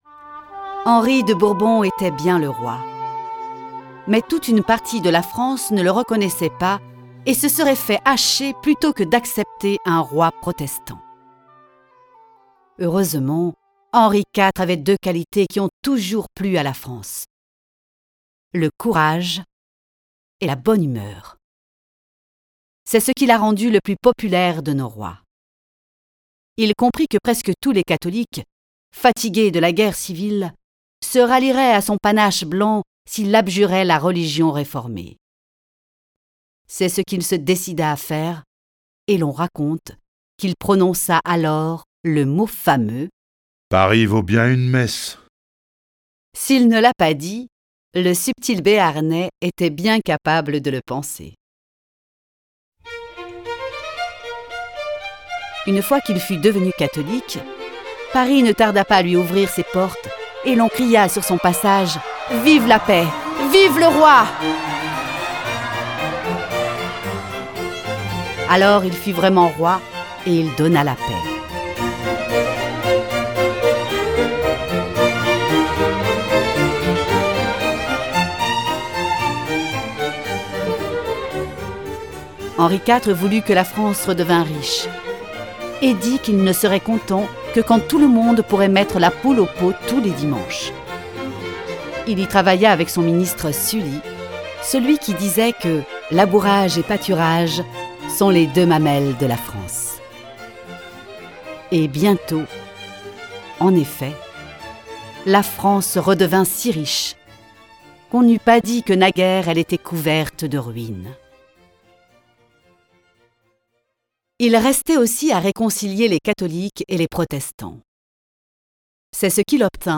Diffusion distribution ebook et livre audio - Catalogue livres numériques
Cette version sonore de l’œuvre de Bainville est animée par cinq voix et accompagnée de plus de cinquante morceaux de musique classique et d’un grand nombre de bruitages.